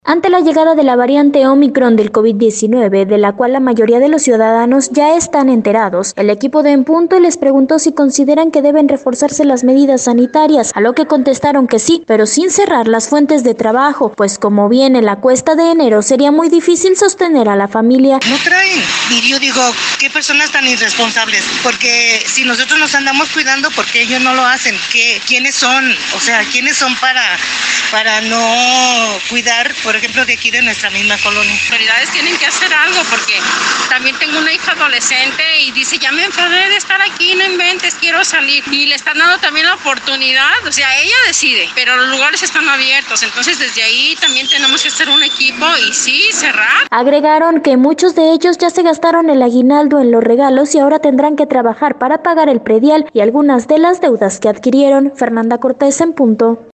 Ante la llegada de la variante ómicron de Covid-19, de la cual la mayoría de los ciudadanos ya están enterados, el equipo de En Punto, les preguntó si consideran que deben reforzarse las medidas sanitarias, a lo que contestaron que sí, pero sin cerrar las fuentes de trabajo, pues como viene la cuesta de enero sería muy difícil sostener a la familia.